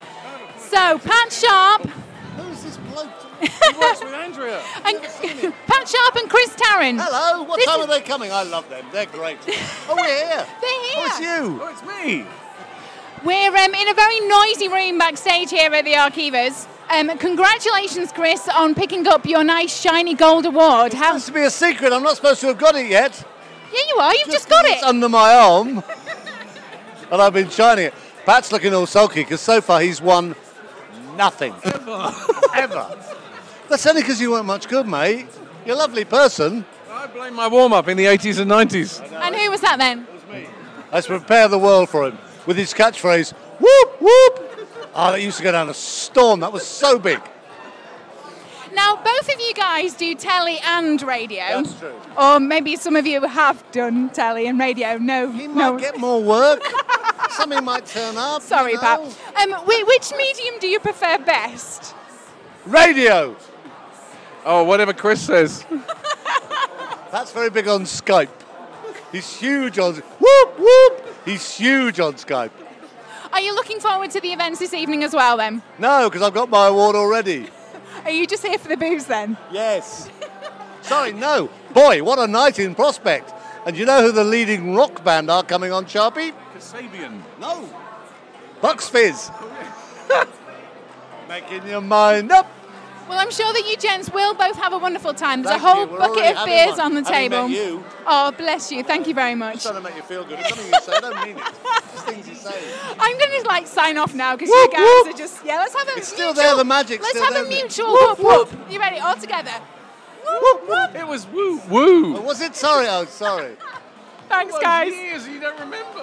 RadioToday Live Interviews / Pat Sharp and Chris Tarrant woop their way through the Arqiva awards
Pat Sharp and Chris Tarrant woop their way through the Arqiva awards